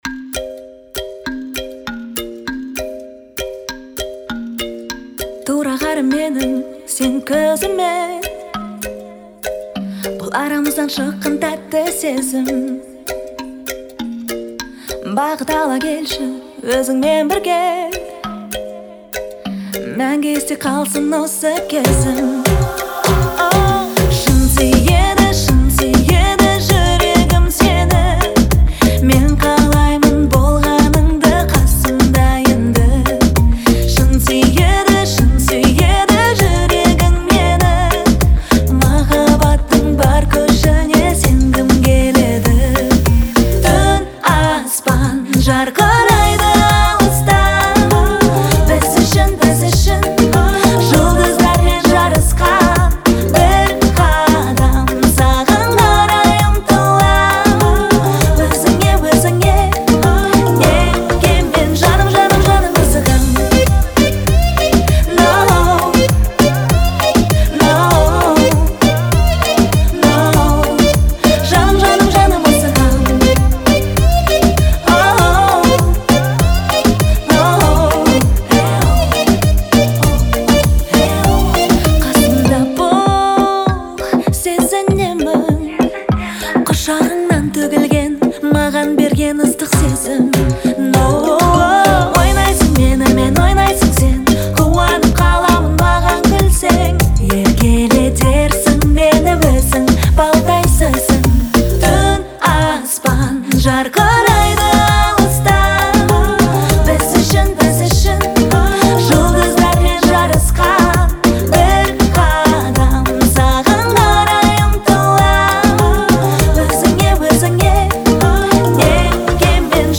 это трогательная композиция в жанре поп